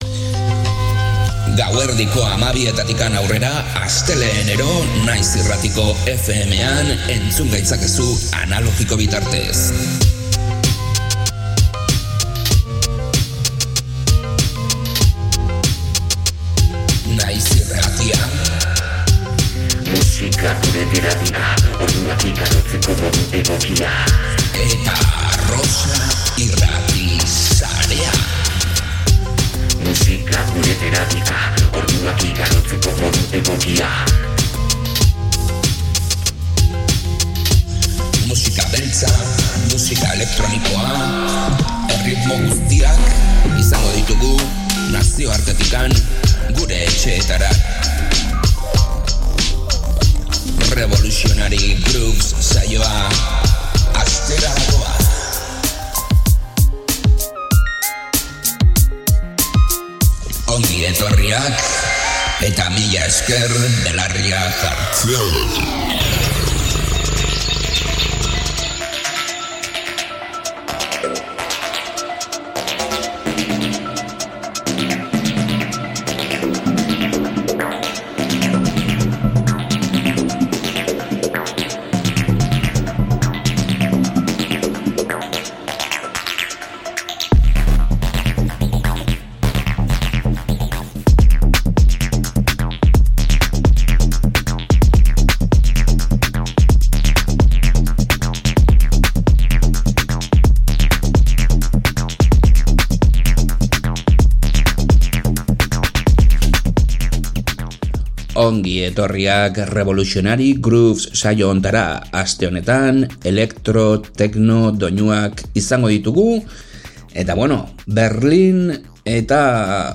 Detroit eta Berlin hirietako Electro Techno doinuak